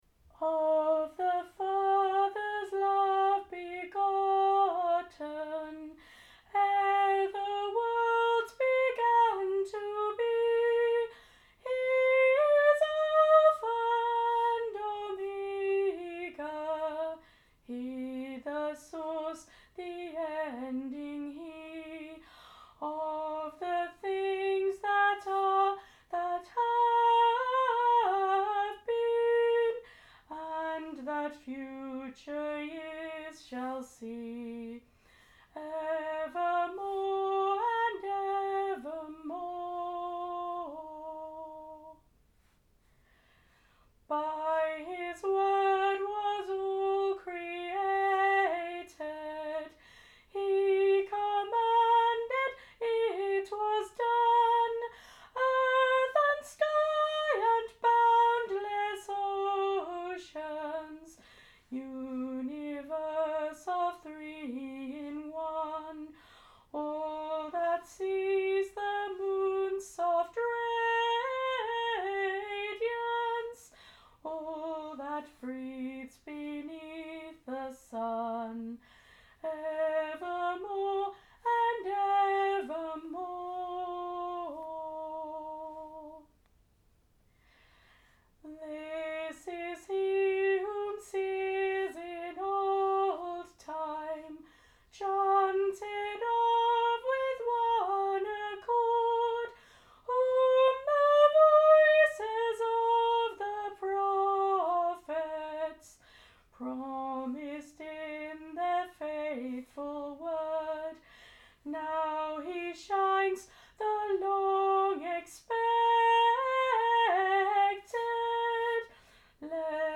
Of the Father's Love Begotten - unaccompanied solo
I was unable to sing at my Church carol service so sent this recording for them to play in my absence.